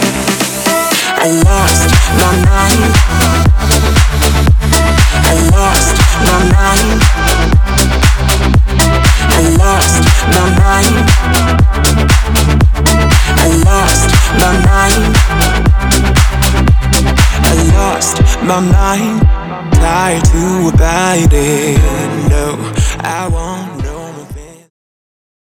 ритмичные
громкие
deep house
зажигательные
Electronic
мощные басы
future house
приятный мужской голос